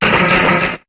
Cri de Solaroc dans Pokémon HOME.